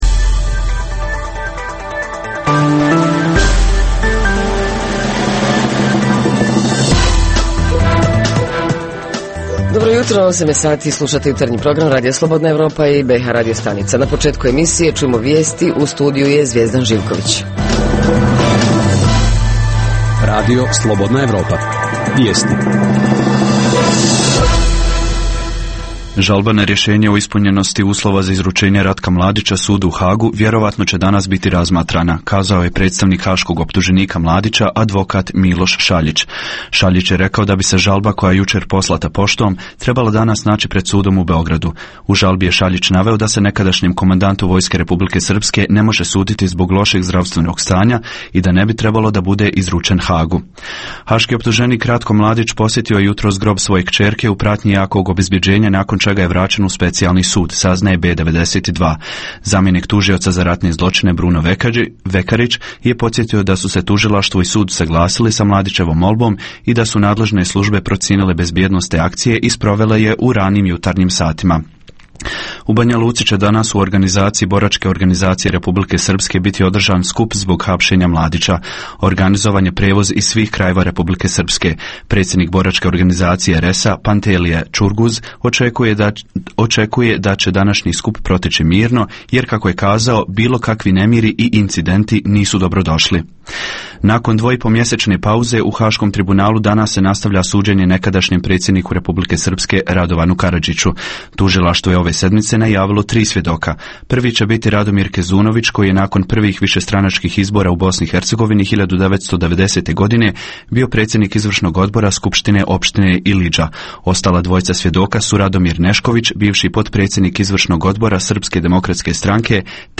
Program za BiH ovog jutra posvećen je Svjetskom danu borbe protiv pušenja. Osim toga, poslušajte izvještaje o dešavanjima u BiH i regionu, a reporteri iz cijele BiH javljaju o najaktuelnijim događajima u njihovim sredinama.
Redovni sadržaji jutarnjeg programa za BiH su i vijesti i muzika.